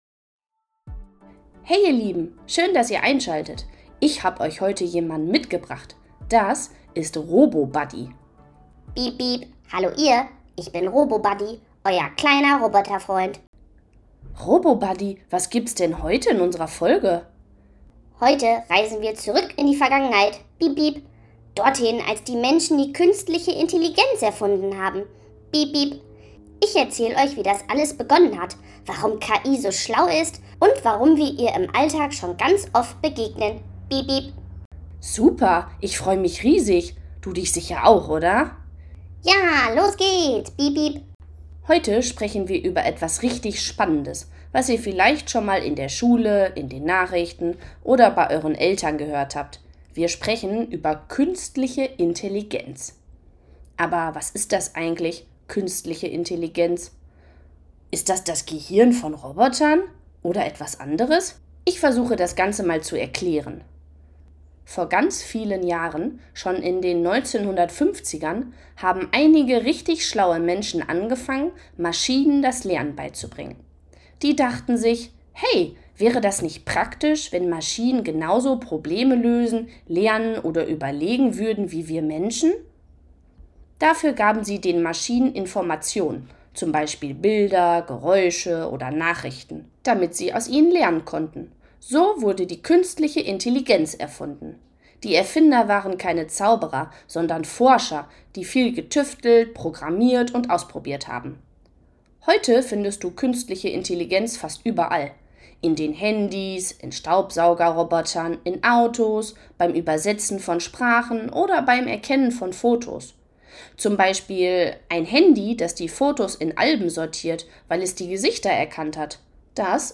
All das erklärt uns heute RoboBuddy, sogar mit einem kurzen